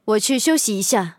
LT-35小破修理语音.OGG